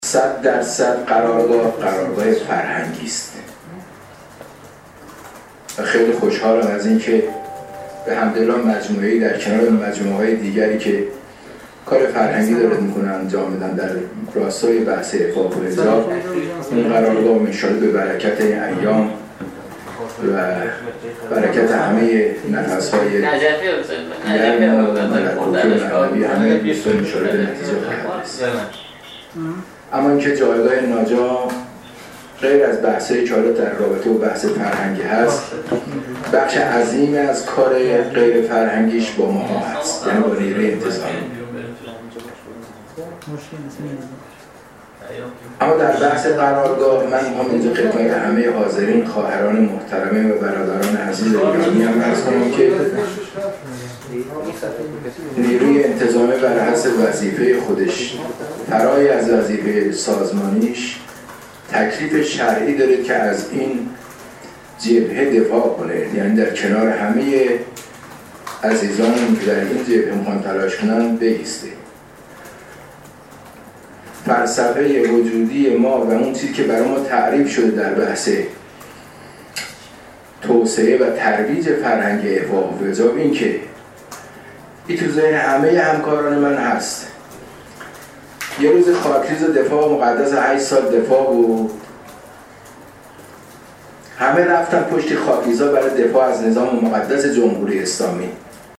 به گزارش خبرنگار خبرگزاری رسا، سردار عبدالرضا آقاخانی فرمانده نیروی انتظامی استان قم عصر امروز در آیین افتتاحیه قرارگاه امر به معروف و نهی از منکر ثارالله استان قم و همایش تقدیر از فعالان امر به معروف و نهی از منکر که در سالن همایش های الغدیر برگزار شد، با اشاره به اینکه فعالیت های قرارگاه ثارالله به صورت فرهنگی انجام می شود، گفت: ما سعی داریم در زمینه عفاف و حجاب در شهر مقدس قم فرهنگ سازی داشته باشیم.